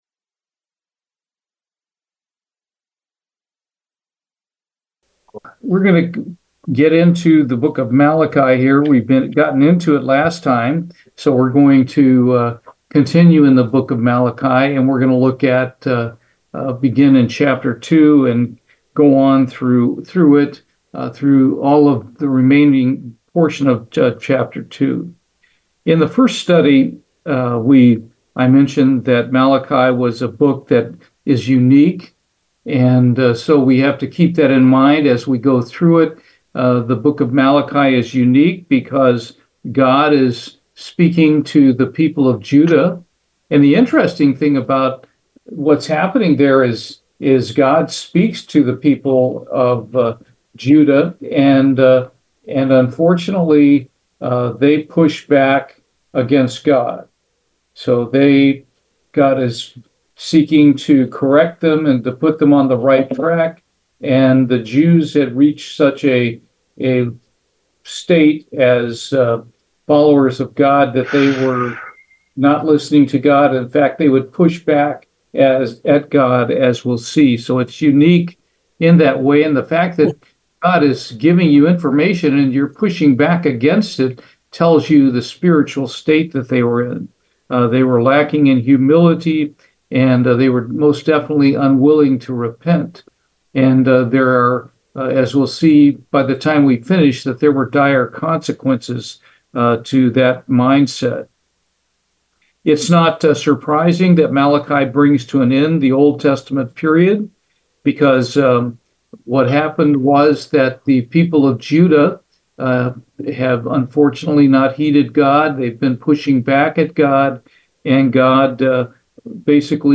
Bible Study, Malachi, Part 3
Given in Houston, TX